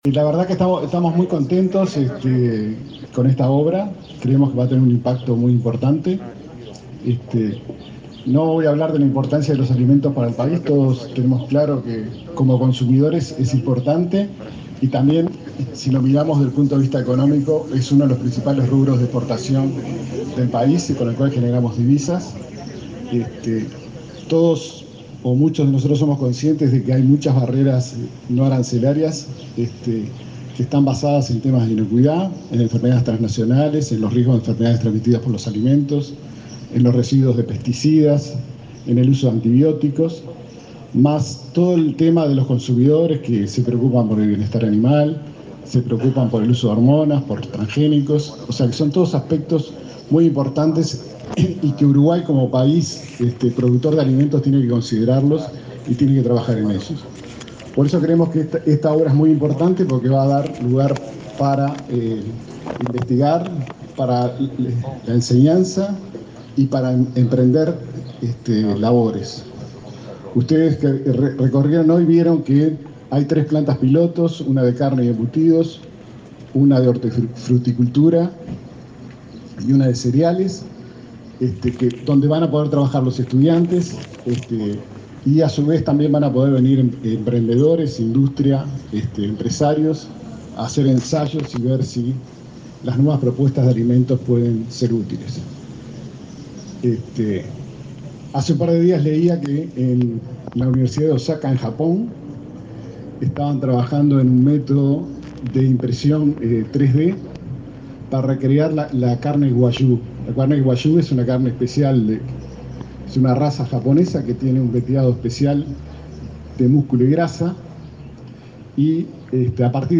Palabras de autoridades en la inauguración de un centro UTEC en Paysandú
El consejero de la Universidad Tecnológica del Uruguay (UTEC), Andrés Gil, y el ministro de Educación y Cultura, Pablo da Silveira, participaron, este